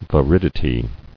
[vi·rid·i·ty]